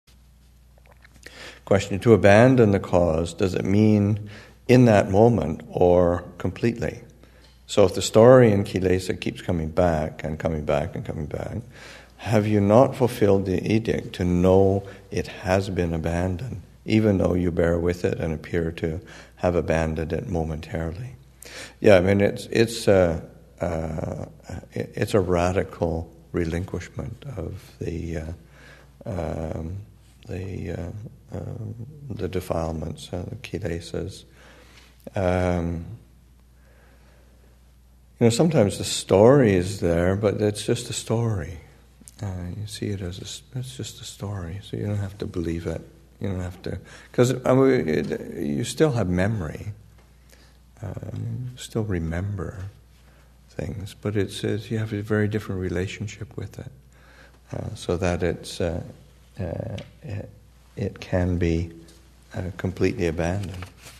2015 Thanksgiving Monastic Retreat, Session 6, Excerpt 2